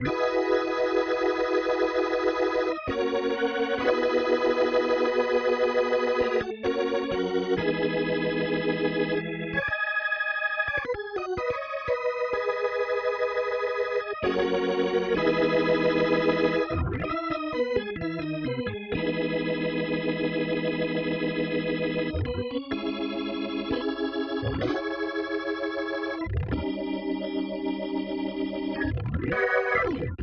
14 organ D.wav